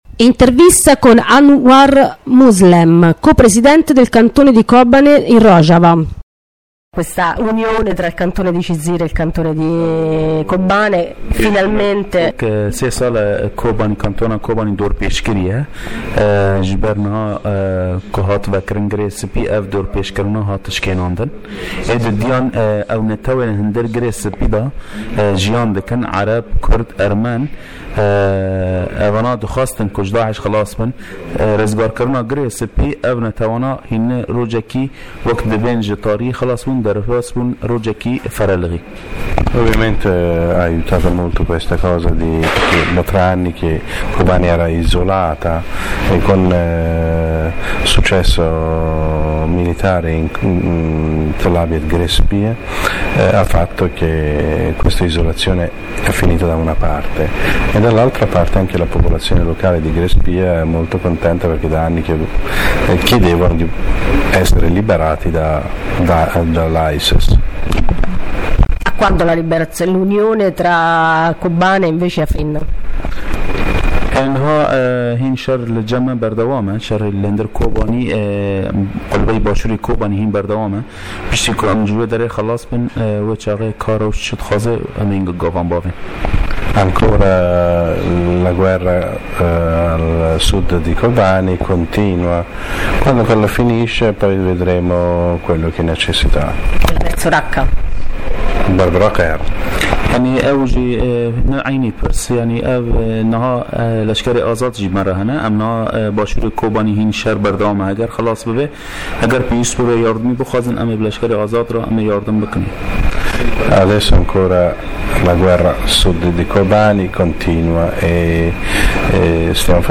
inter_kurdi.mp3